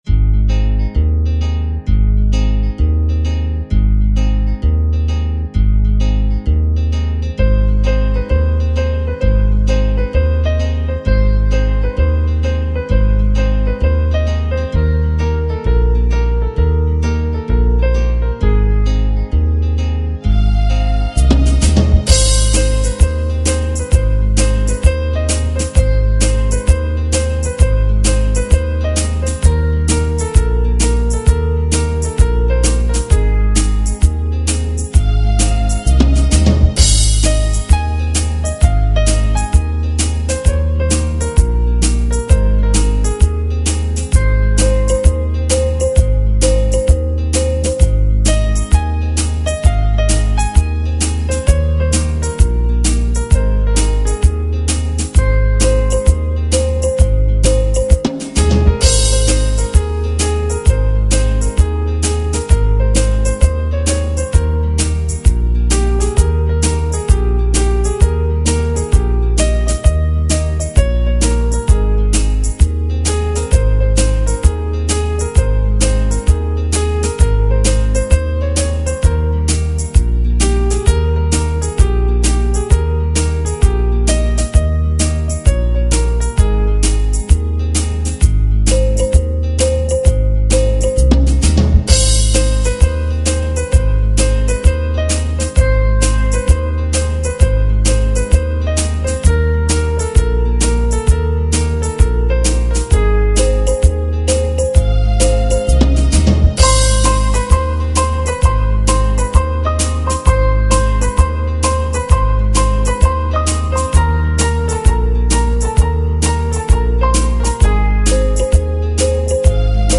Instrumental Songs > Old Bollywood